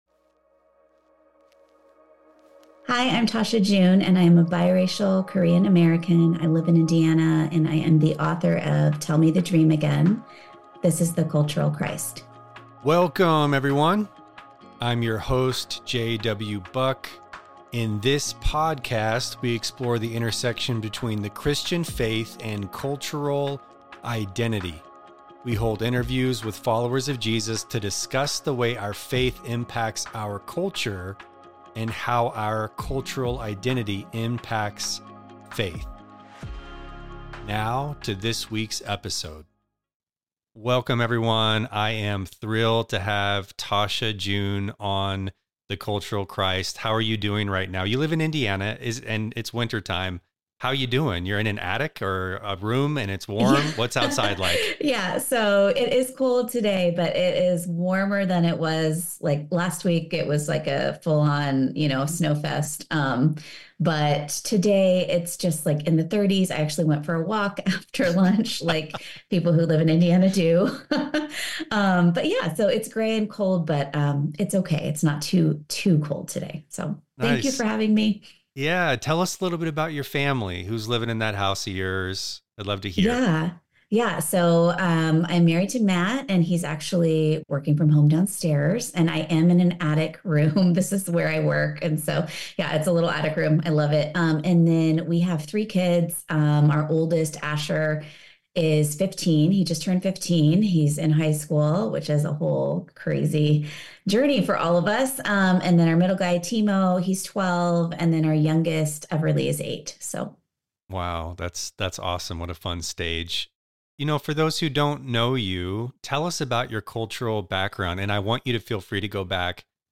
Together, they discuss the importance of recognizing and celebrating cultural differences within the Christian faith and how understanding one’s cultural identity is essential to an authentic relationship with God. Tune in for a compelling conversation about faith, culture, and belonging.